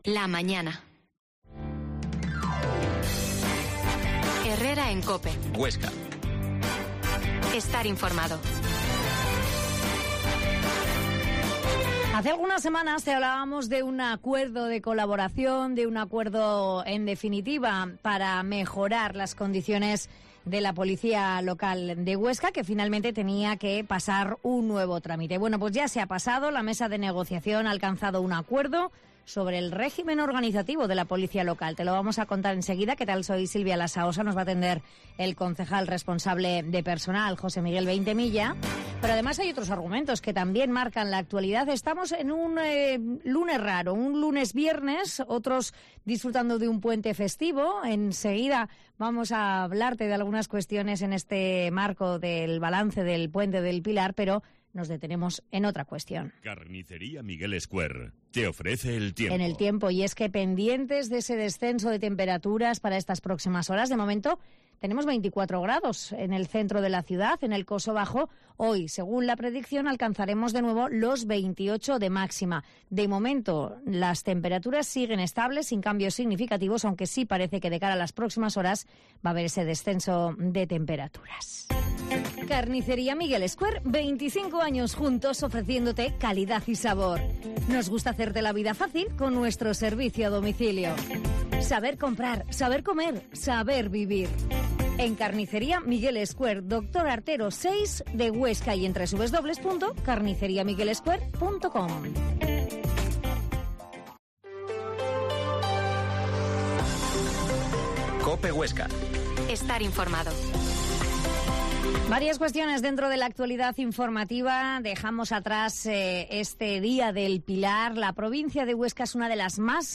Herrera en COPE Huesca 12.50h Entrevista al concejal de personal, José Miguel Veintemilla